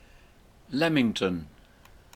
Royal Leamington Spa, commonly known as Leamington Spa or simply Leamington[note 1] (/ˈlɛmɪŋtən/